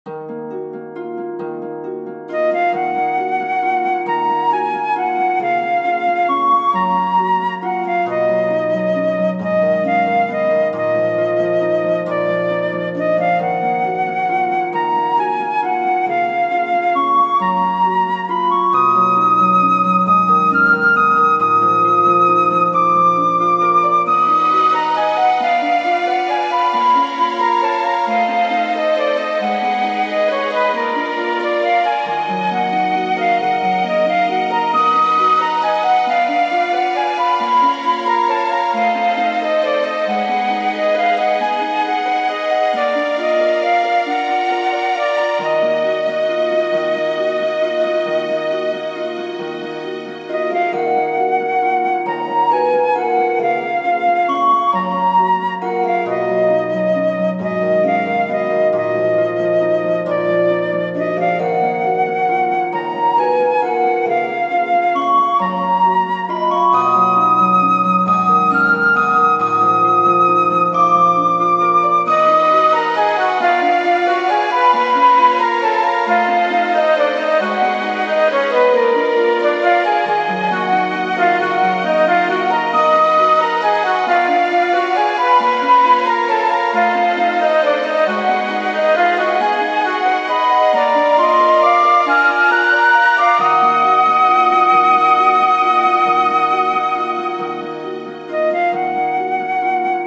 ogg(L) 悲しい 幻想的 フルート
ドラマティックなフルートとハープ。